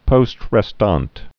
(pōst rĕ-stänt)